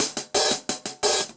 Index of /musicradar/ultimate-hihat-samples/175bpm
UHH_AcoustiHatC_175-01.wav